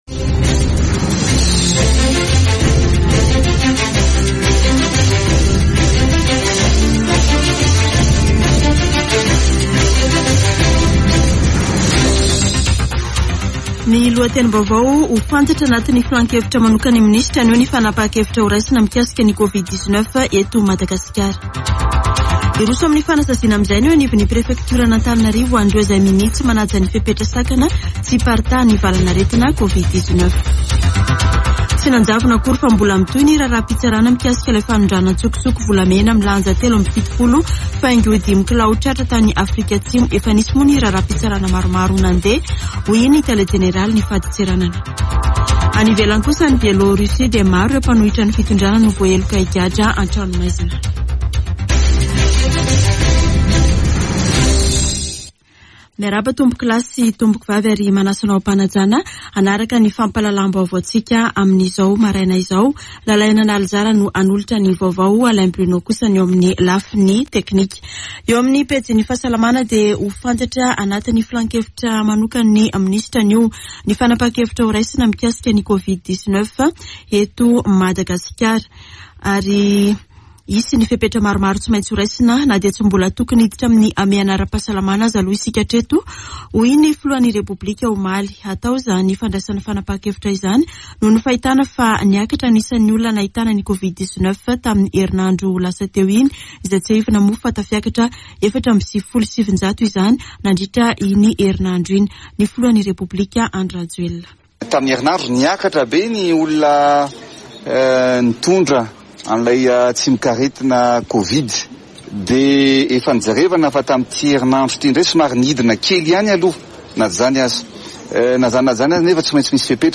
[Vaovao maraina] Alarobia 15 desambra 2021